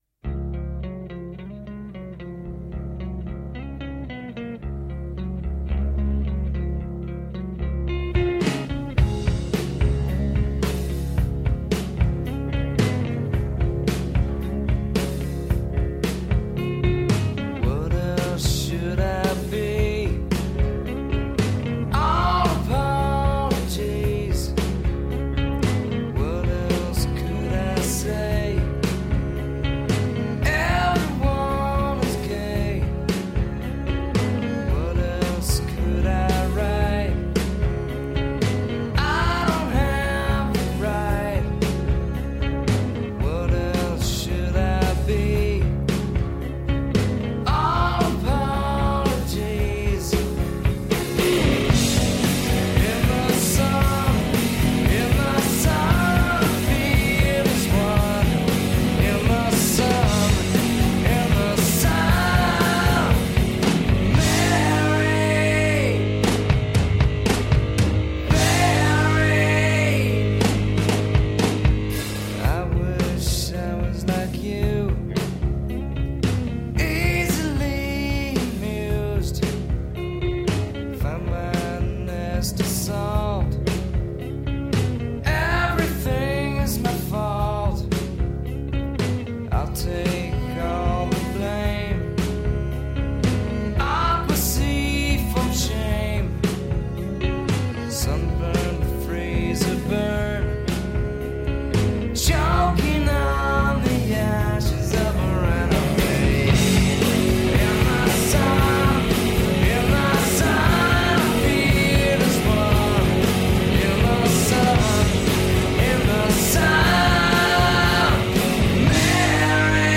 Radio Theatre: All Apologies (Audio) Aug 25, 2016 shows Radio Theatre Curated by many contributors. broadcasts All Apologies : Dec 29, 2016: 3pm - 3:30 pm Original radio theatre, and new productions from P...